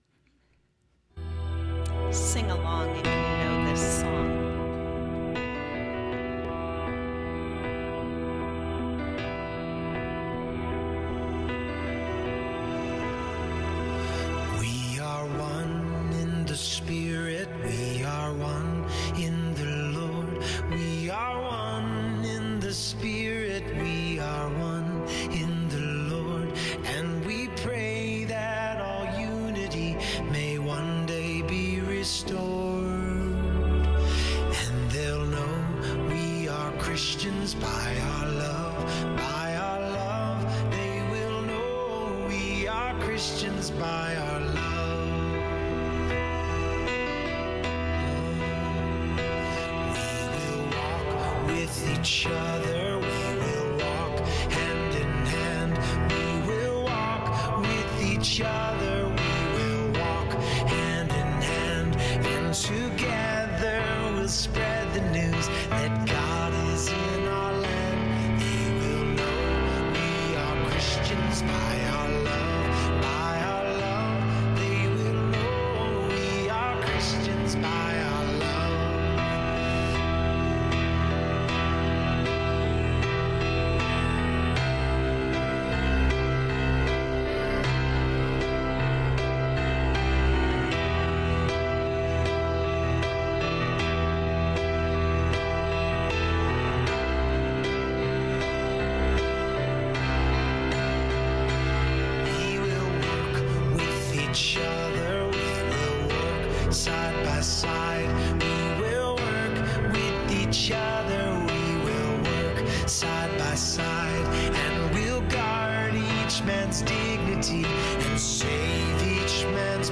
Sermons | The River Christian Church